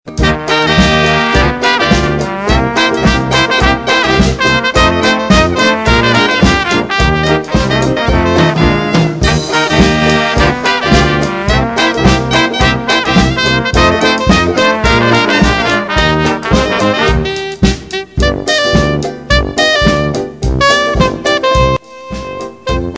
They specialize in Dixieland and Ragtime jazz.